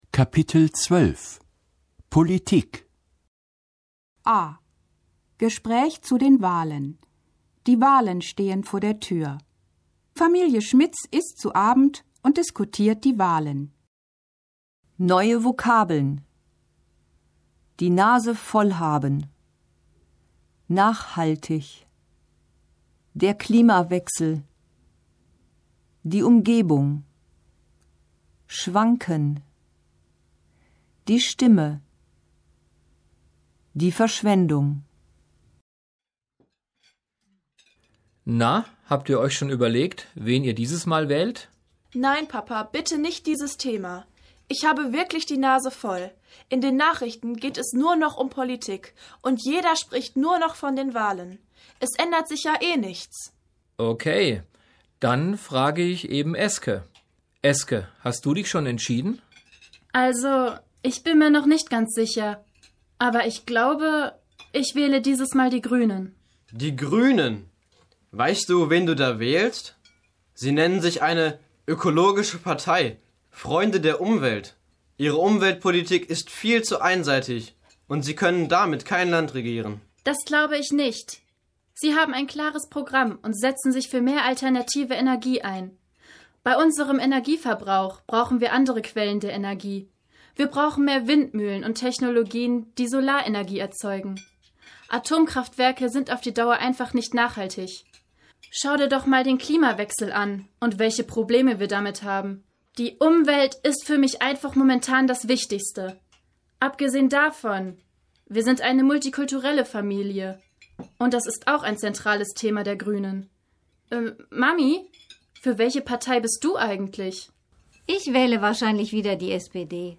Gespräch zu den Wahlen (3217.0K)